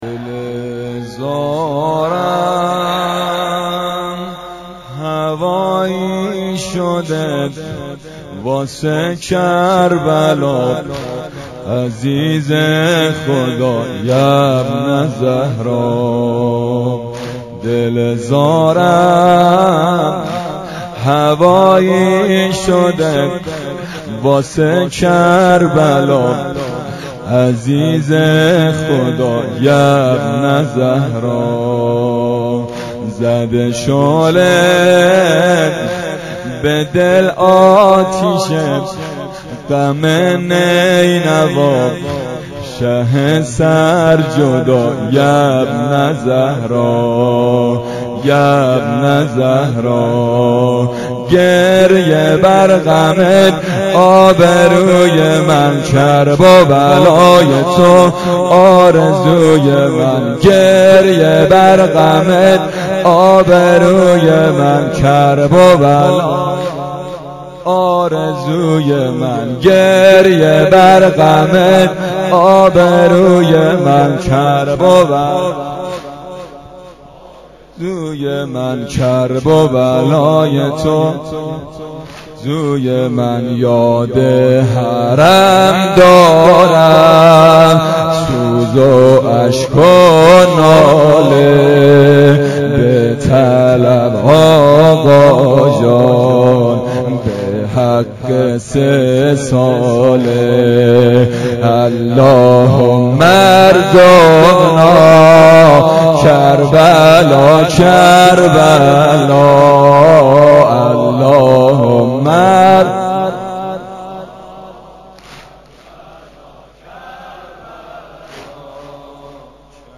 شب عاشورا محرم 93 حسینیه معراج الشهدا
واحد تند1: دل زارم هوایی شده